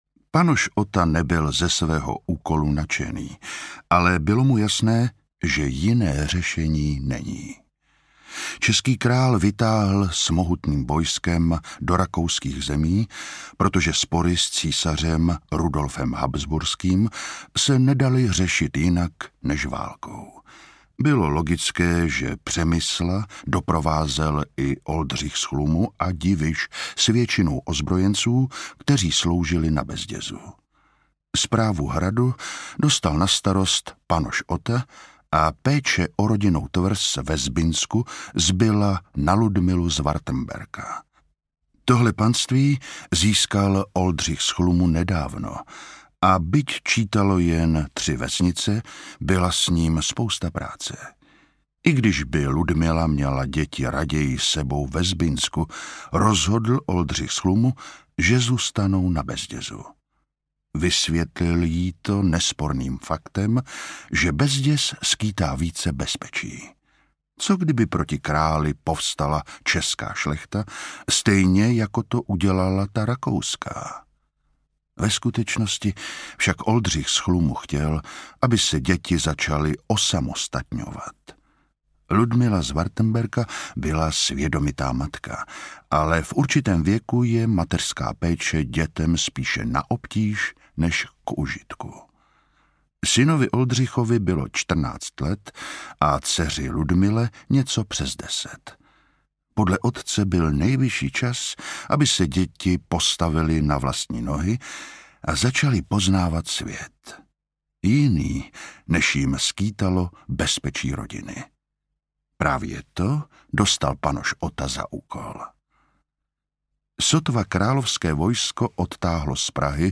Jablko nepadlo daleko od stromu audiokniha
Ukázka z knihy